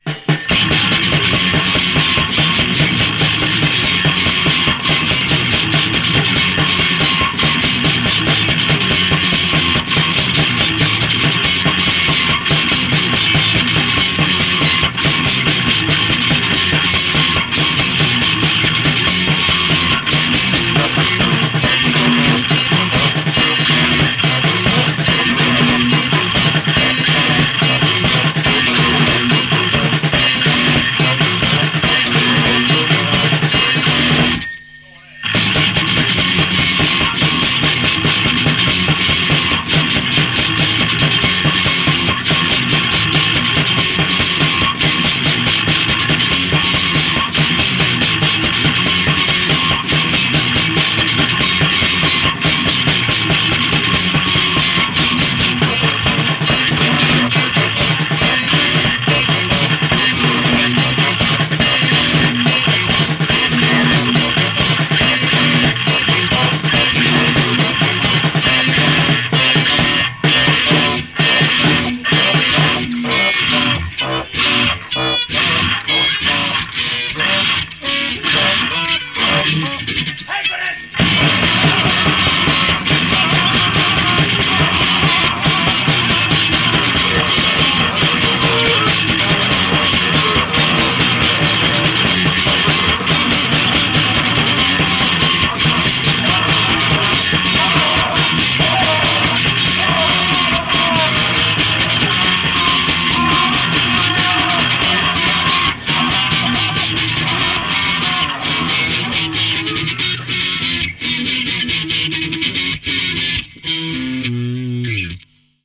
spastisempi no wave -tuuttaus
Does it need vocals?
Dizzying.